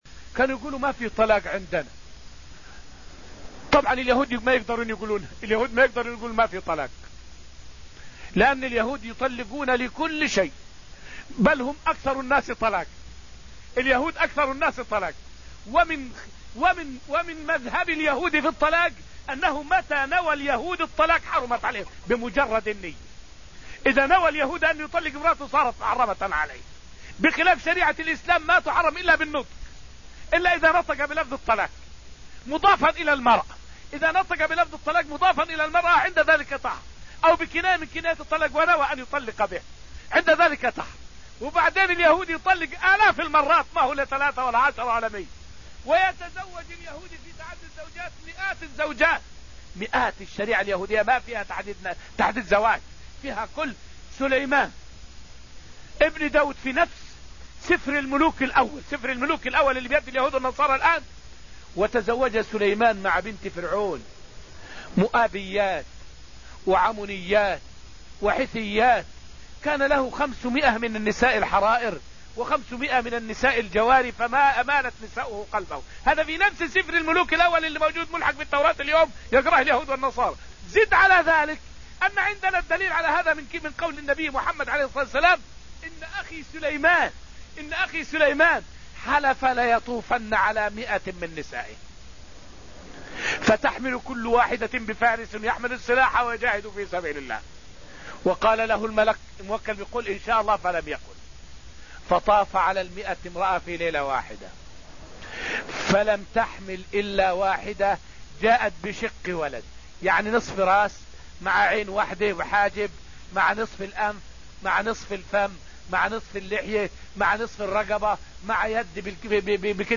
فائدة من الدرس التاسع من دروس تفسير سورة الحديد والتي ألقيت في المسجد النبوي الشريف حول الزواج والطلاق في دين اليهود.